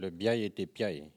Elle provient de Saint-Gervais.
Catégorie Locution ( parler, expression, langue,... )